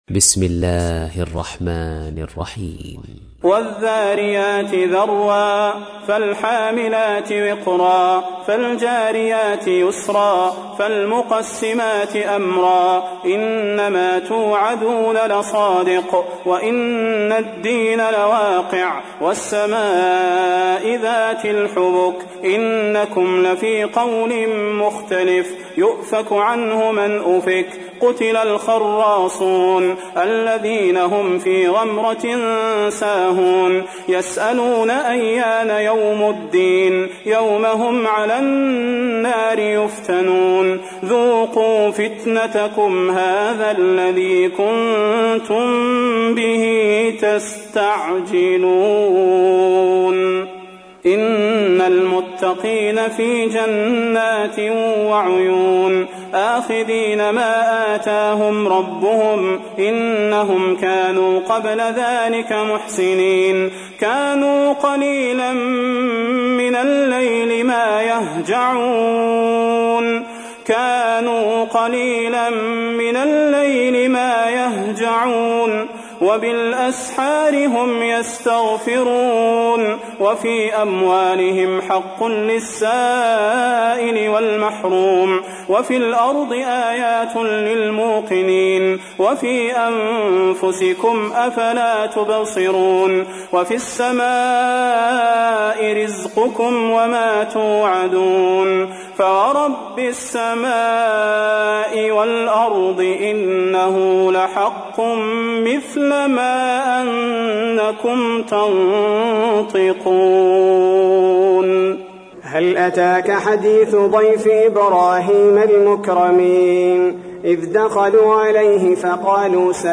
تحميل : 51. سورة الذاريات / القارئ صلاح البدير / القرآن الكريم / موقع يا حسين